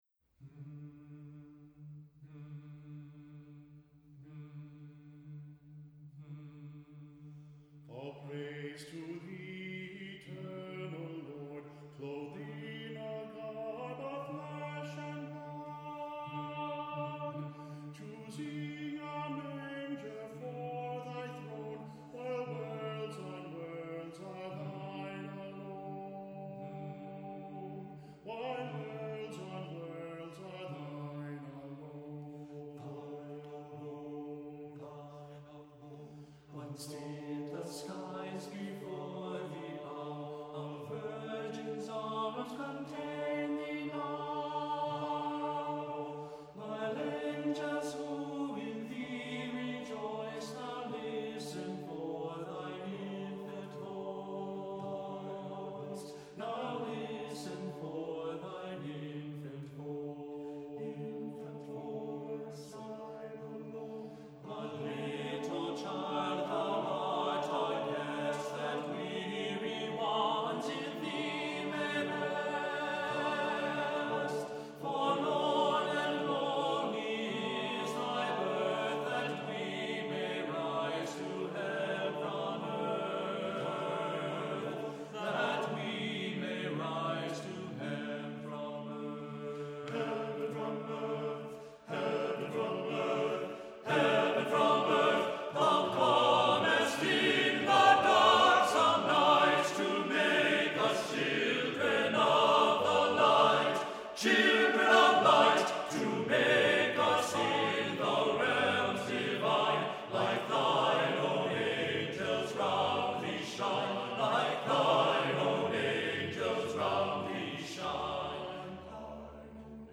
Accompaniment:      None
Music Category:      Choral
carol